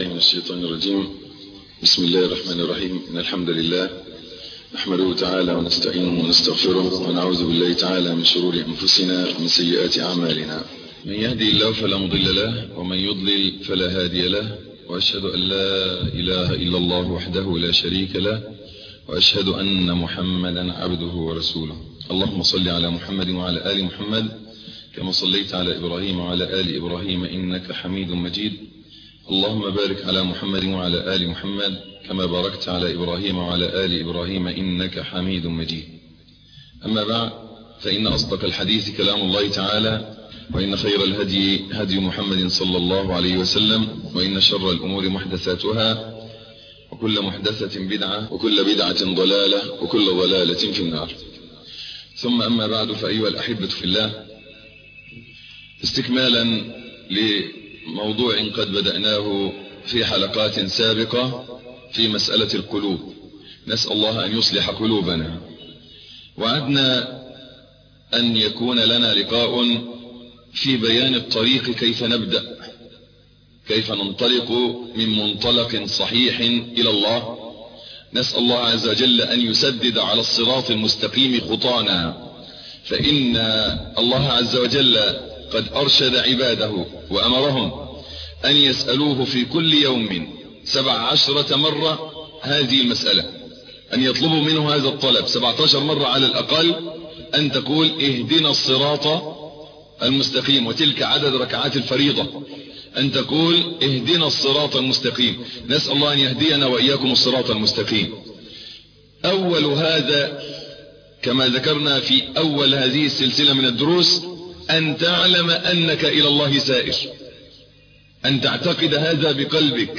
الدرس الخامس - فضيلة الشيخ محمد حسين يعقوب